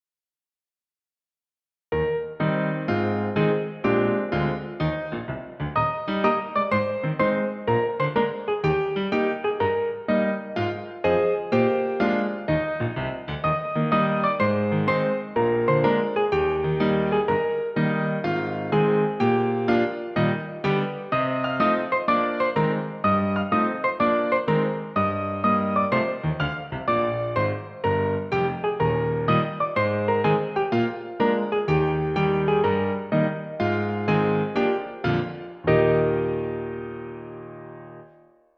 Piano accompaniment
Musical Period 19th century British, Australian, American
Tempo 125
Rhythm March
Meter 4/4